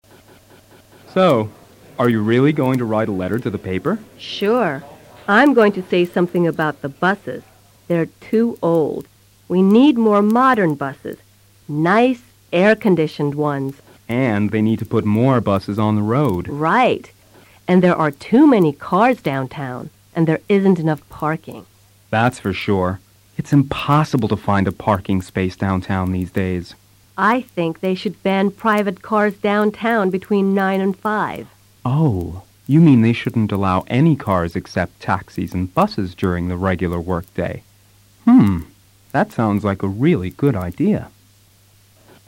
Activity 18: Este es un ejercicio de comprensión auditiva. Escucha el final de la conversación anterior. Concéntrate en interpretar QUÉ OTRAS COSAS NEGATIVAS detectan Sonia y Mark en la ciudad donde viven.